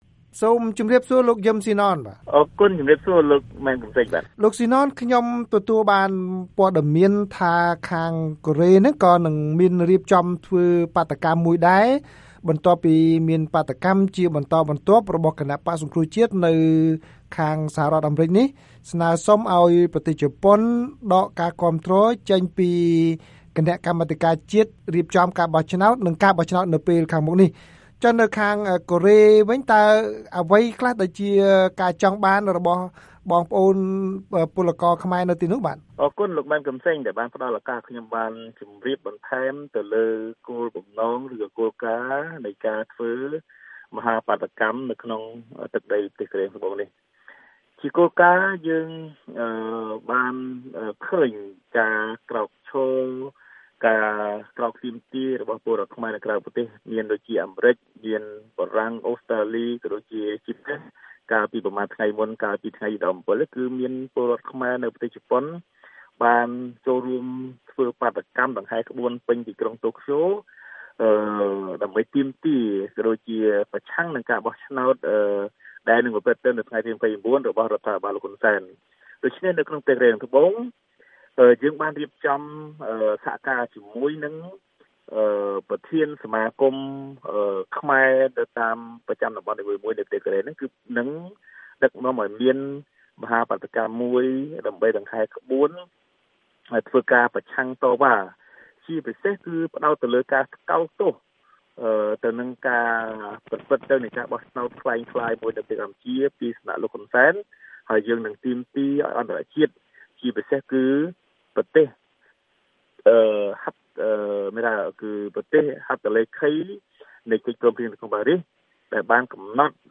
បទសម្ភាសន៍VOA៖ ពលរដ្ឋខ្មែរនៅកូរ៉េខាងត្បូងគ្រោងធ្វើបាតុកម្មប្រឆាំងការបោះឆ្នោតជាតិ